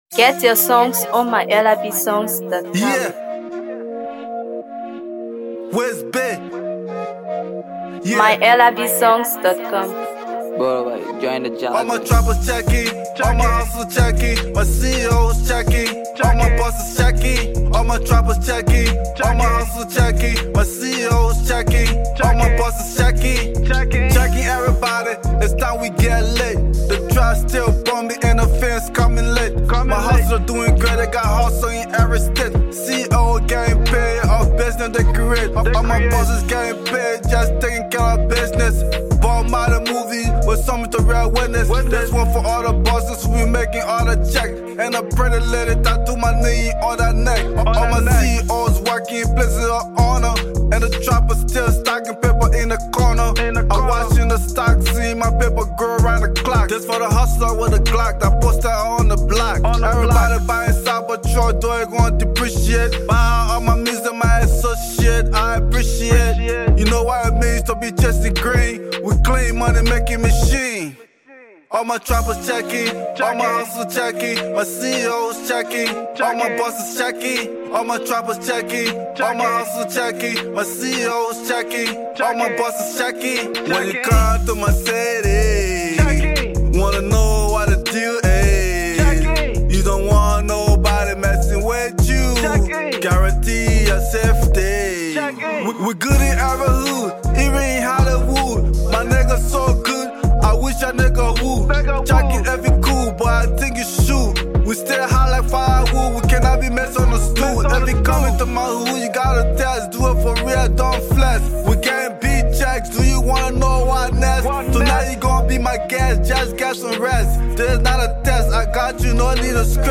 Hip HopMusicTrapco
Dope Trap Banger